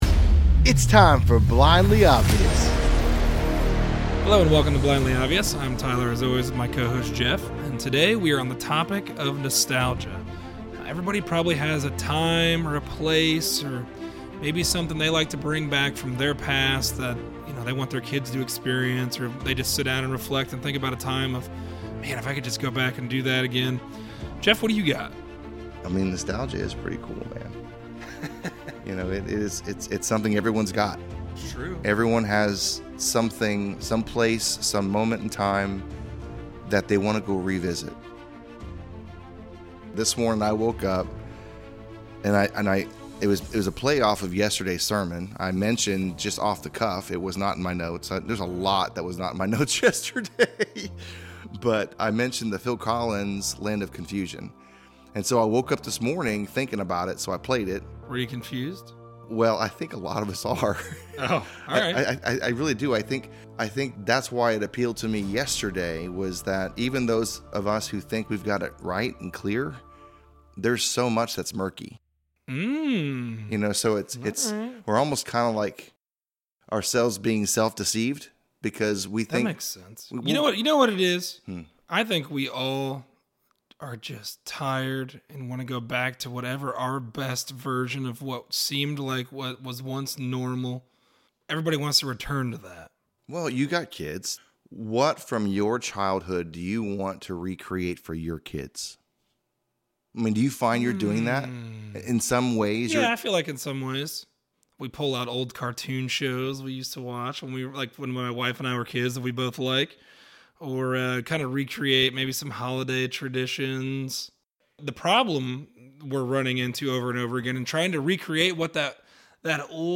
A conversation on nostalgia.